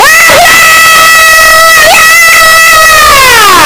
Ooh Gloria Estourado - Botão de Efeito Sonoro